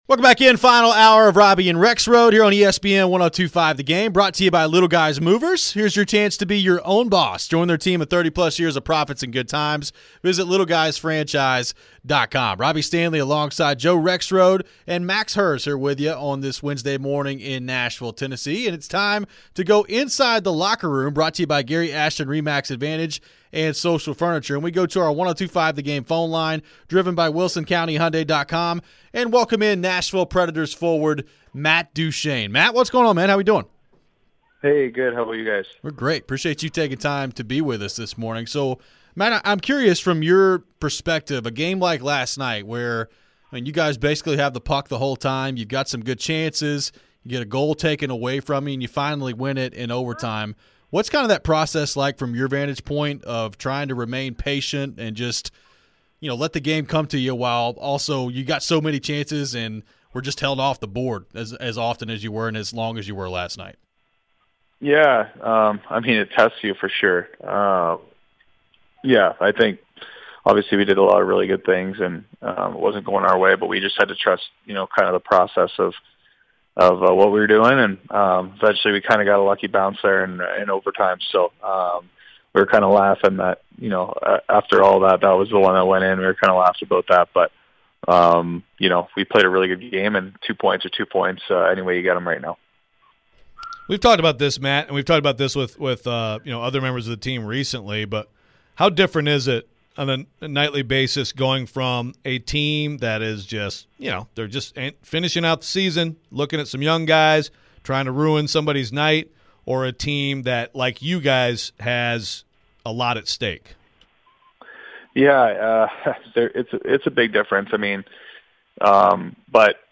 player interview segment